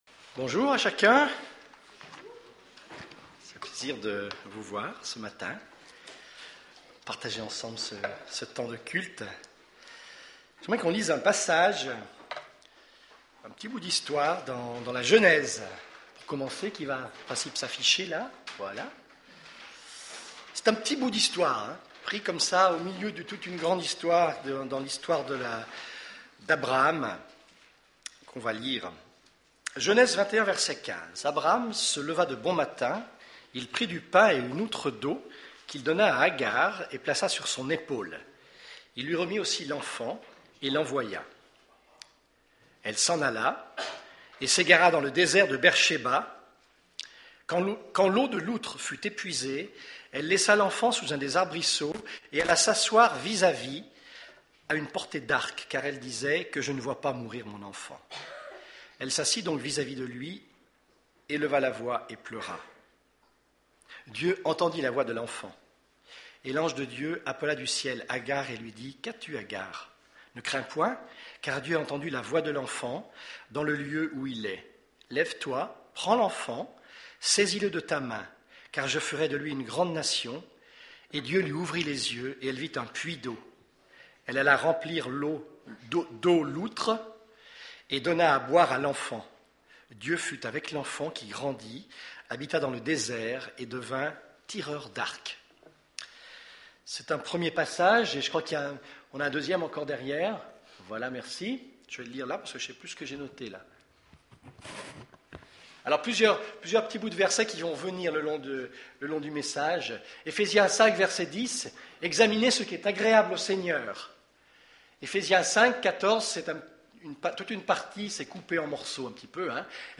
Culte du 27 novembre